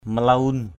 /mə-la-un/ malaun mluN [Cam M] 1.